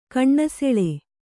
♪ kaṇṇa seḷe